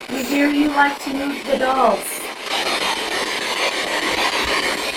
Spirit Box Clip 7 Villisca Axe Murder House Spirit Box Clip 7 This amazing young female response was captured in the Stillinger girls' downstairs bedroom through our SB11 spirit box. We had placed a doll on the bed in a pose, hoping that one of the kids would move it.
Girl responds about moving the dolls My best guess here is, "Everybody did that!"